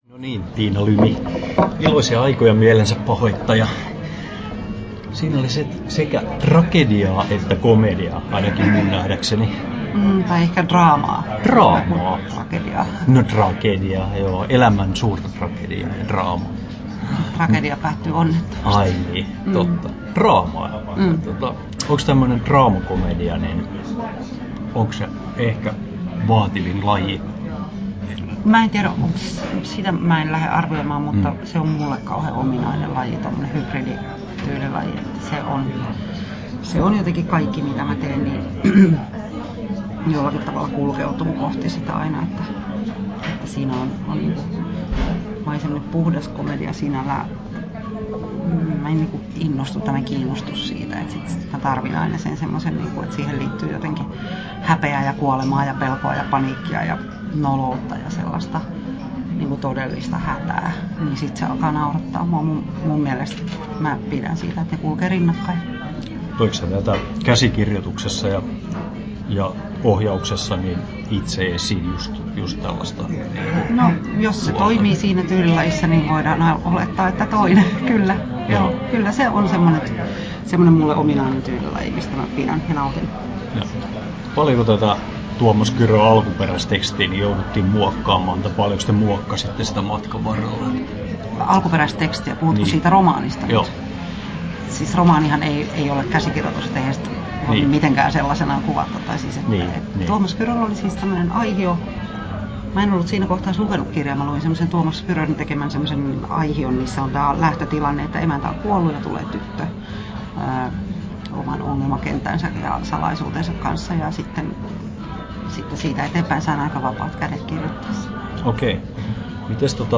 Haastattelussa Tiina Lymi Kesto: 8'48" Tallennettu: 21.08.2018, Turku Toimittaja